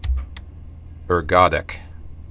(ûr-gŏdĭk)